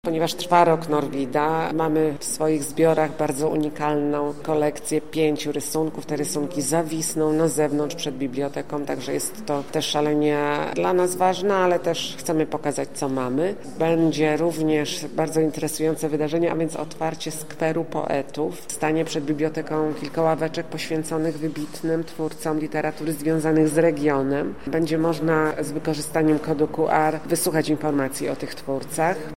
podczas dzisiejszego briefingu prasowego